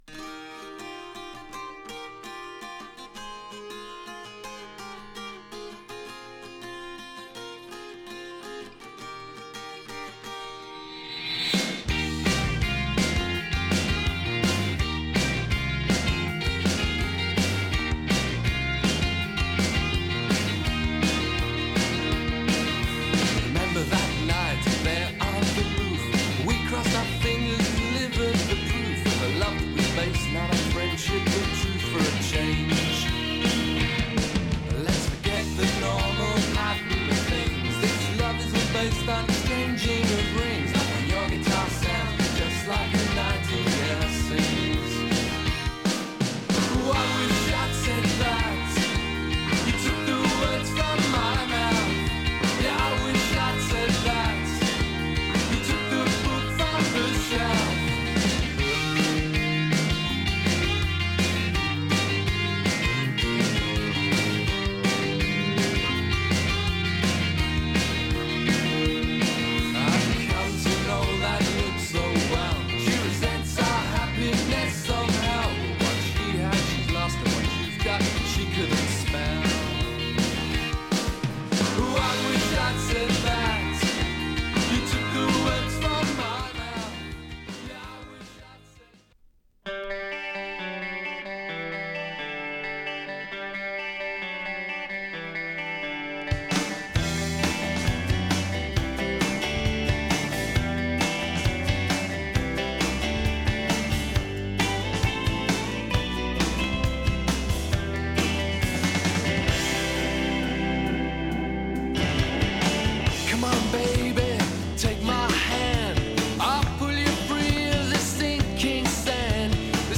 瑞々しいギターを全面に押し出たサウンドが多く